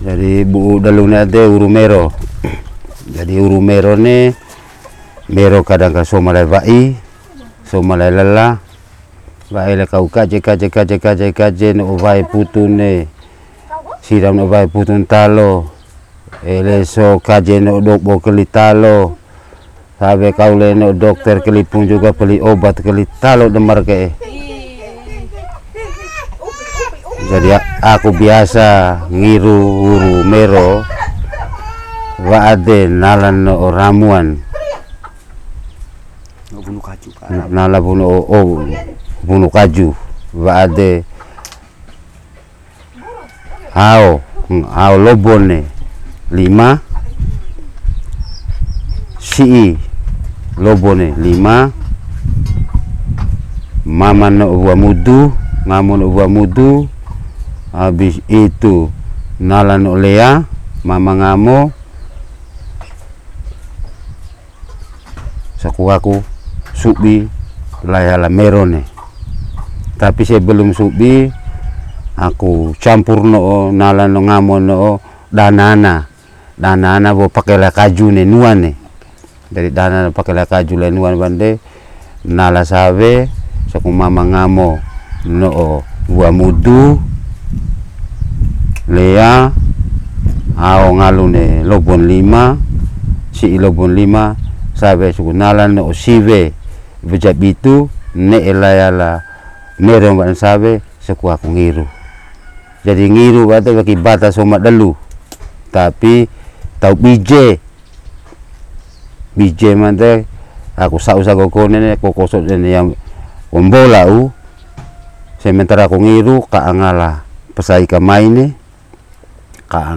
Several you men hanging around after a while.
Recording made in kampong Tetu reti, Uwa.